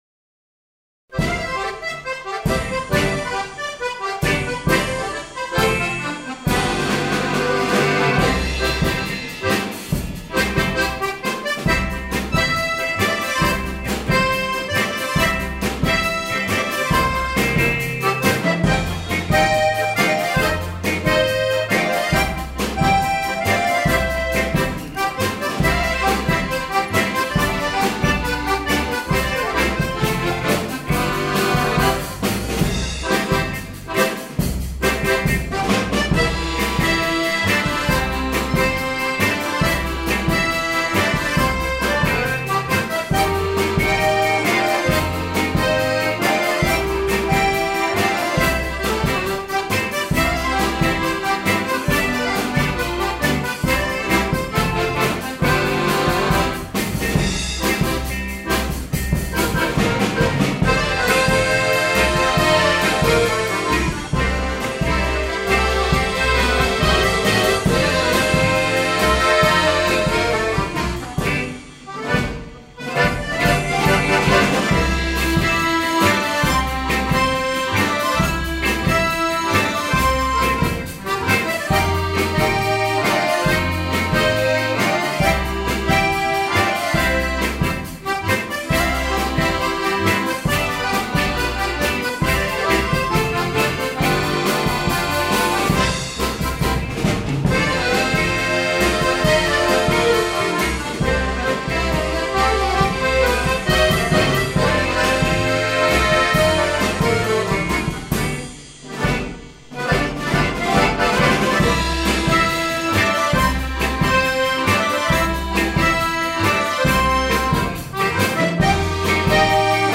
2010 – Akkordeonorchester Neustadt bei Coburg e. V.